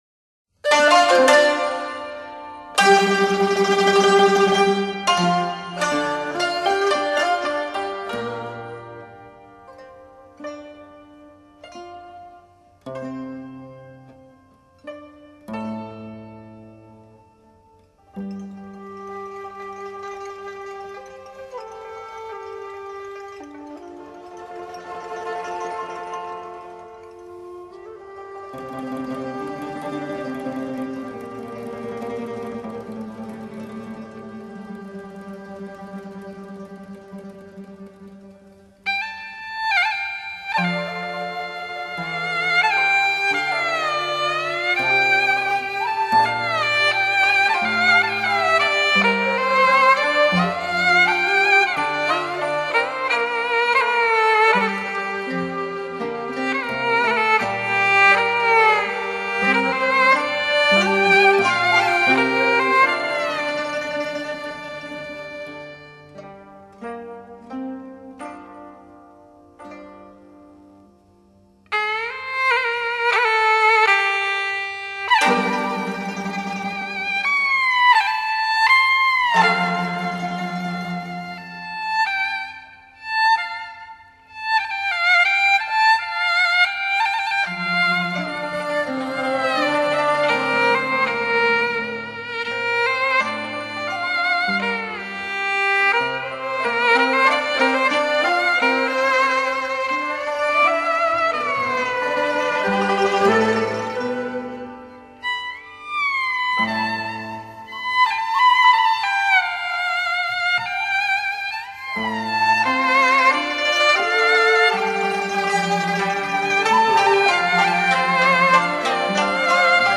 分类：中国民乐
高胡